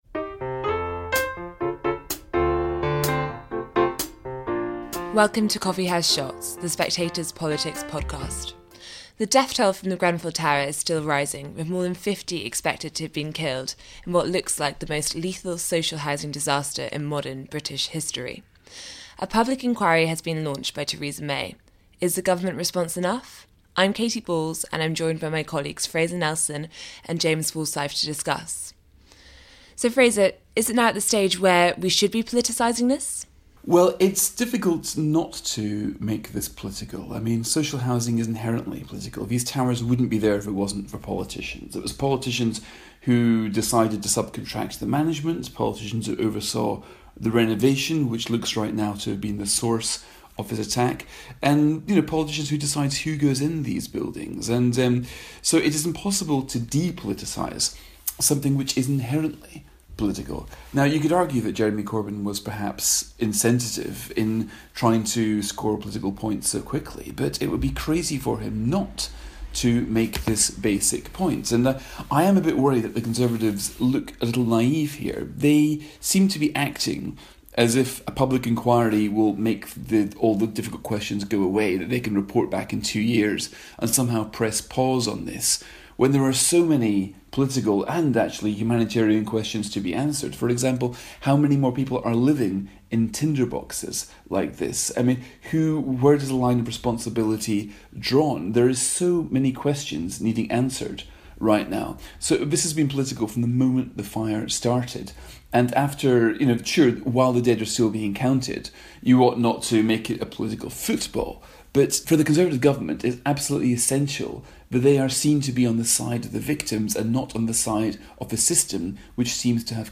Coffee House Shots The Spectator News, Politics, Government, Daily News 4.4 • 2.1K Ratings 🗓 16 June 2017 ⏱ ? minutes 🔗 Recording | iTunes | RSS Summary On the tragic fire at Grenfell Tower in West London, and the political repercussions. With Fraser Nelson and James Forsyth. Presented by Katy Balls.